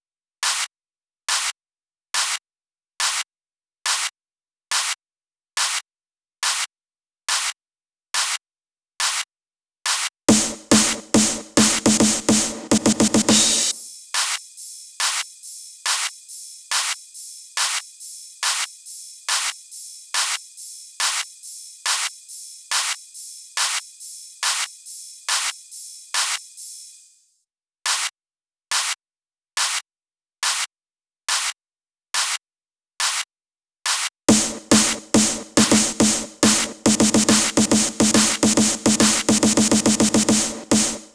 VTDS2 Song Kit 09 Pitched Freaking Drum Mix.wav